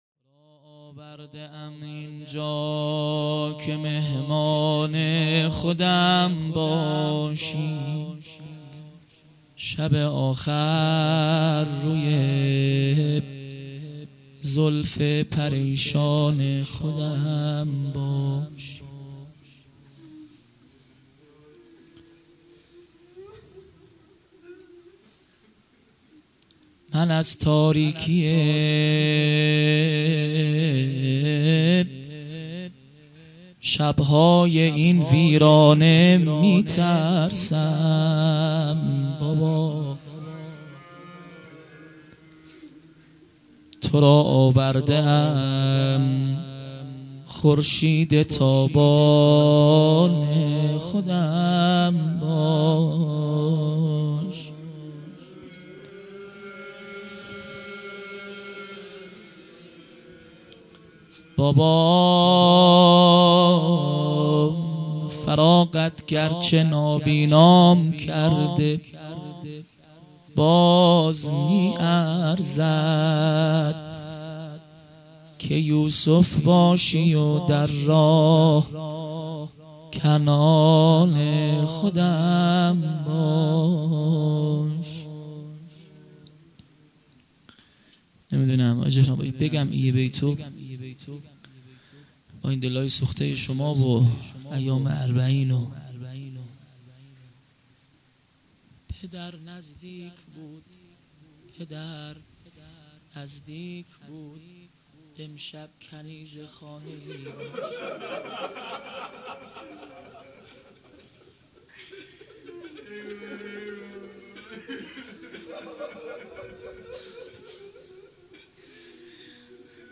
0 0 روضه